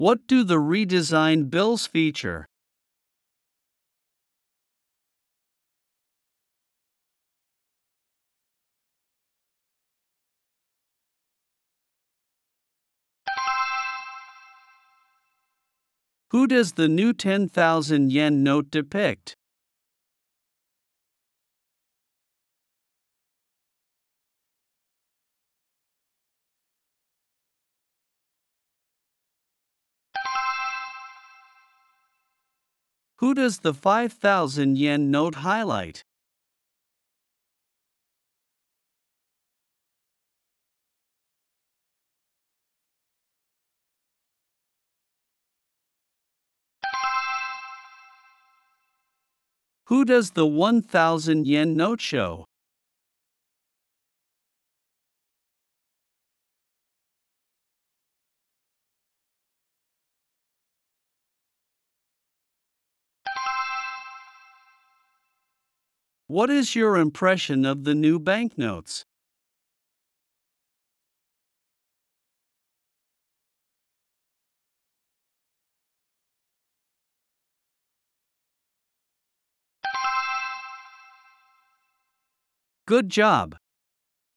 プレイヤーを再生すると英語で5つの質問が1問ずつ流れ、10秒のポーズ（無音部分）があります。
10秒後に流れる電子音が終了の合図です。
10秒スピーチ質問音声